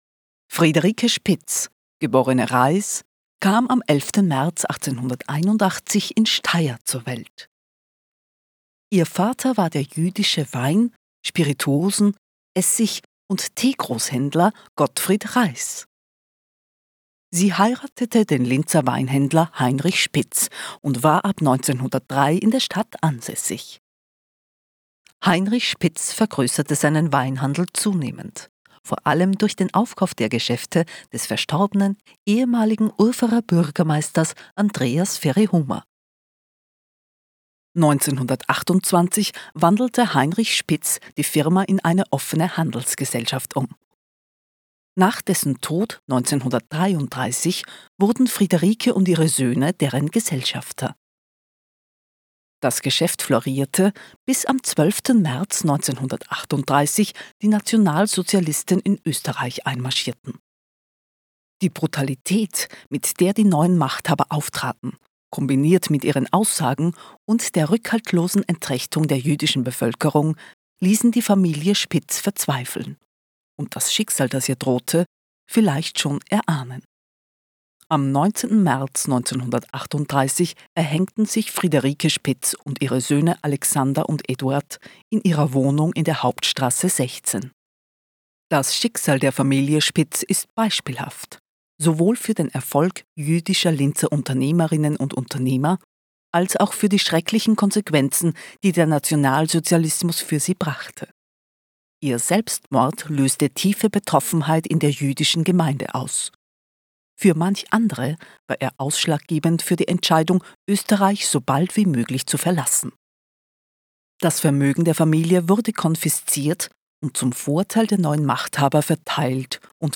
Audioguide Friederike Spitz Englisch